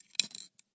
天然钱币 " 天然金属钱币声音1
描述：真实现金支付的声音。硬币落在ceramical罐子里。
标签： 下降 银行 落下 硬币 小猪 下降 货币 支付 现金 金属 硬币 支付 黄金 交易
声道立体声